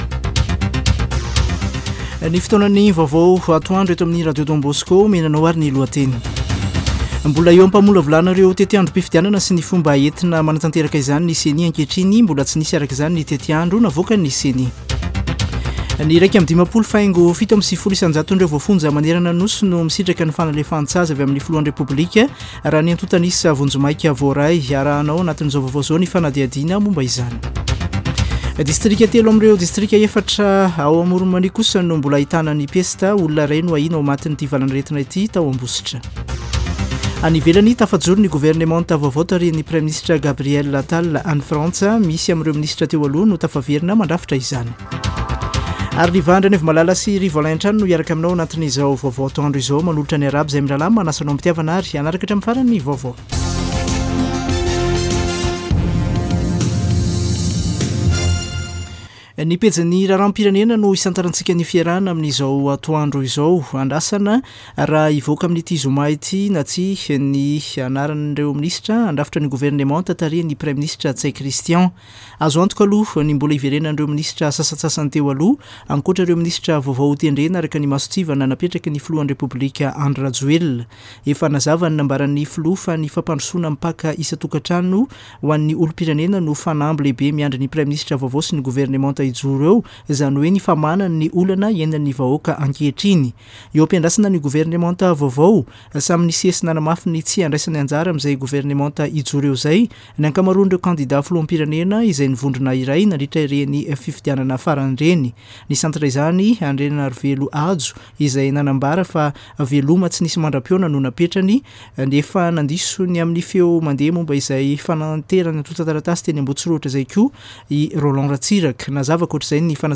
[Vaovao antoandro] Zoma 12 janoary 2024